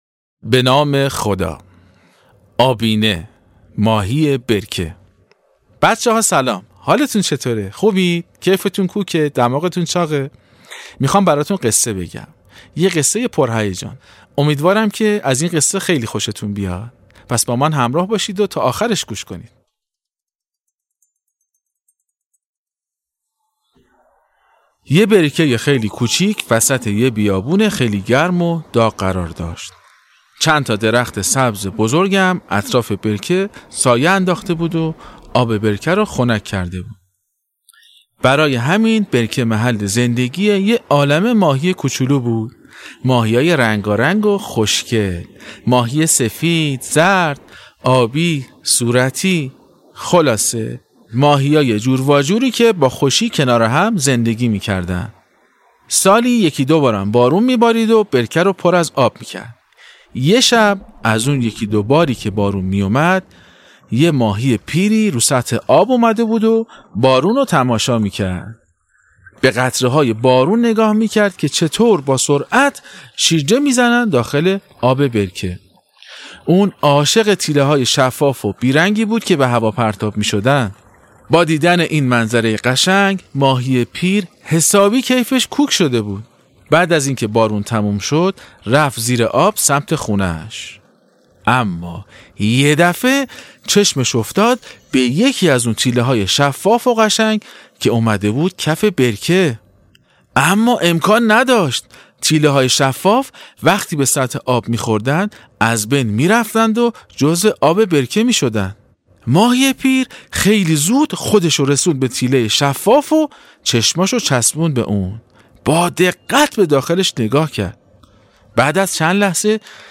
داستان ماهی ای که از یه جریان خیلی مهم برامون صحبت میکنه. باهم قسمت اول از داستان صوتی آبینه رو بشنویم.